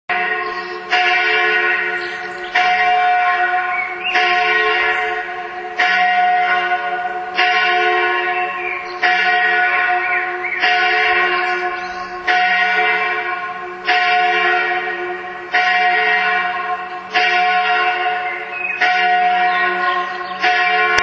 Tonaufnahme der Glocke „Zehnerin“ in Marling